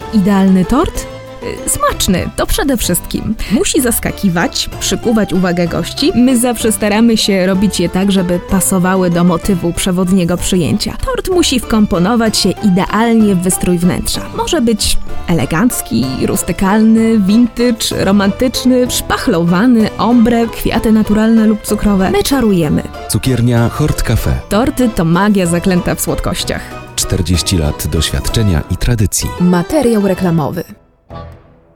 Female 20-30 lat
Nagranie lektorskie